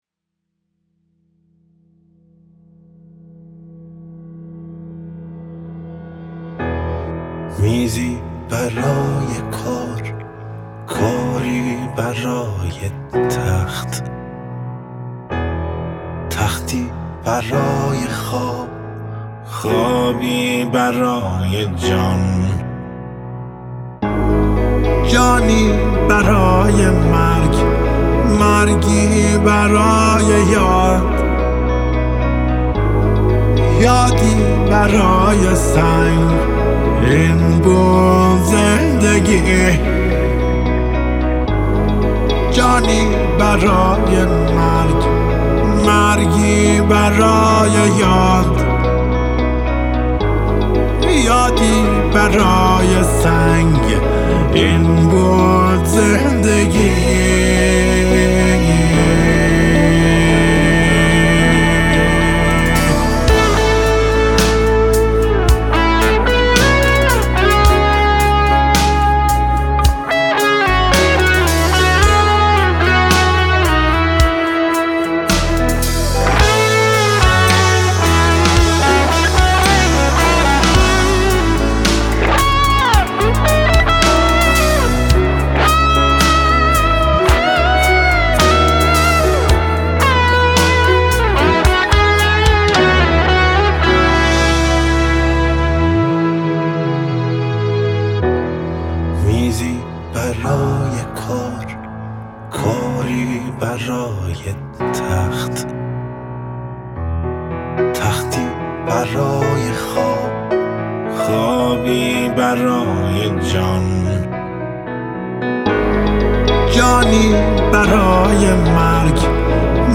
موسیقی پاپ
گیتار الکتریک